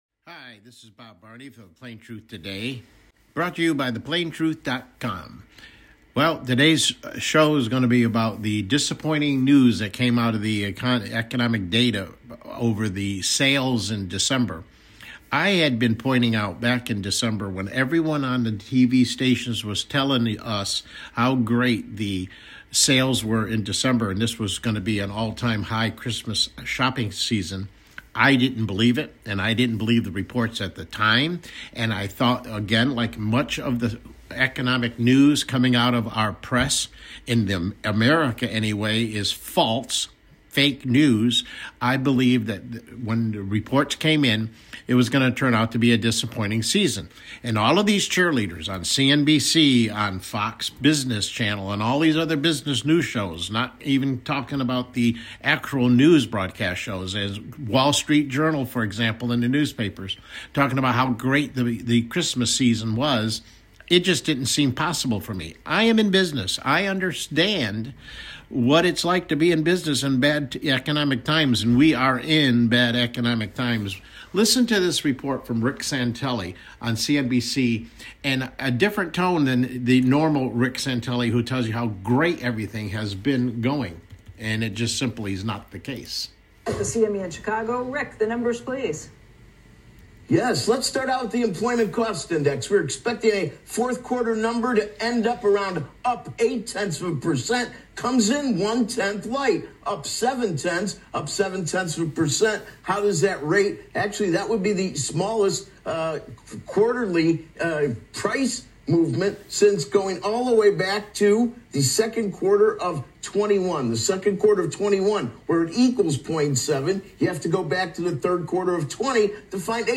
CLICK HERE TO LISTEN TO THE PLAIN TRUTH TODAY MIDDAY BROADCAST: The Economy Still Not Looking Good for You and Me